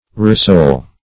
Rissole \Ris`sole"\, n. [F., fr. rissoler to fry meat till it is